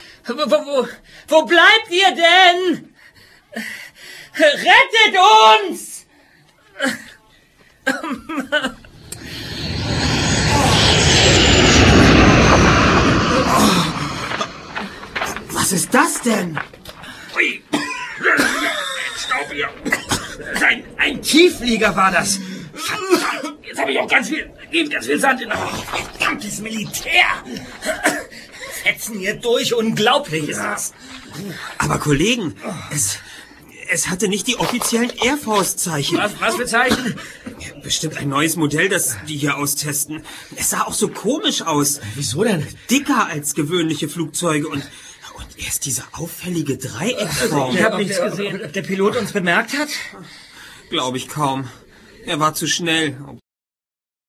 - Todesflug | Physical CD Audio drama
Erzähler - Matthias Fuchs
Justus Jonas, Erster Detektiv - Oliver Rohrbeck
Peter Shaw, Zweiter Detektiv - Jens Wawrczeck
Bob Andrews, Recherchen und Archiv - Andreas Fröhlich